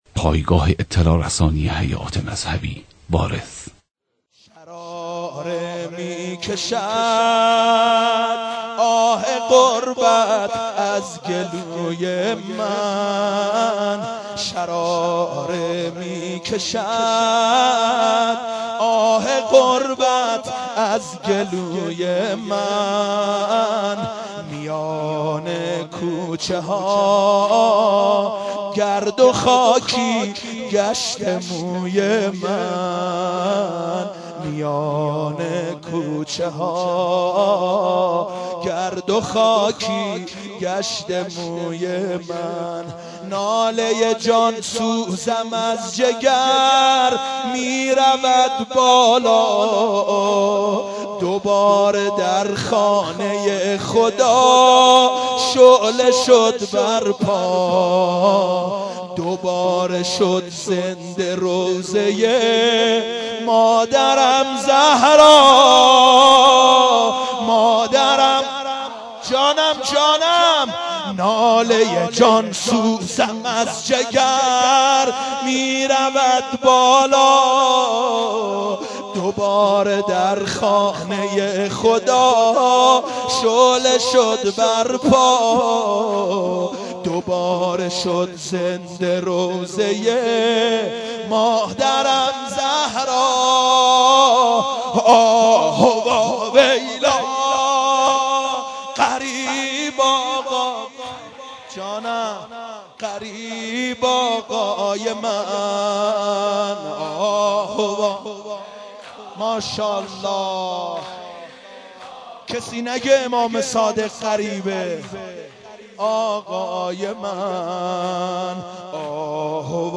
مداحی حاج سید مهدی میرداماد به مناسبت شهادت امام صادق (ع)